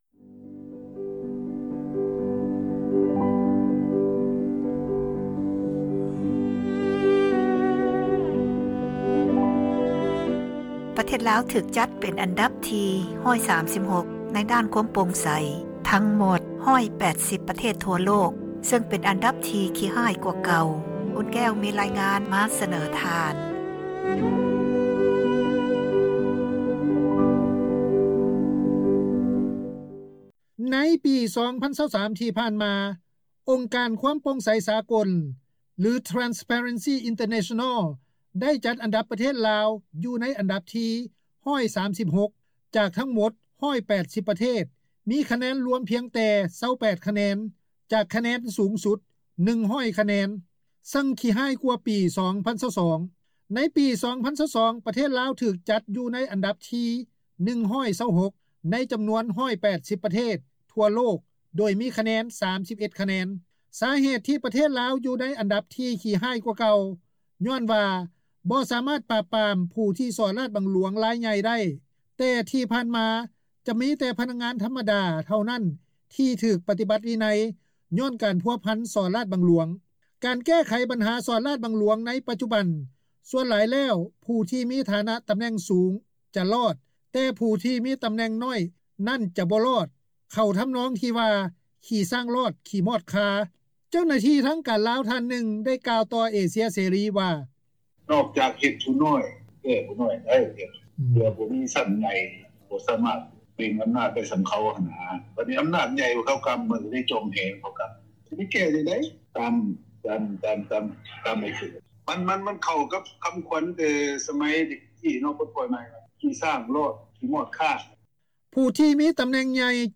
ເຈົ້າໜ້າທີ່ທາງການລາວ ທ່ານນຶ່ງ ໄດ້ກ່າວຕໍ່ວິທຍຸ ເອເຊັຽເສຣີ ວ່າ:
ນັກທຸຣະກິຈ ທ່ານນຶ່ງ ໄດ້ໃຫ້ສໍາພາດຕໍ່ເອເຊັຽເສຣີ ວ່າ:
ປະຊາຊົນ ລາວ ທ່ານນຶ່ງ ໄດ້ກ່າວຕໍ່ວິທຍຸ ເອເຊັຽເສຣີ ວ່າ: